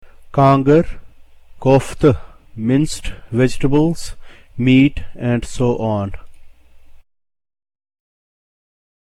Pronunciation and Illustrations
K, k is pronounced the way one would pronounce K in the English word KILL.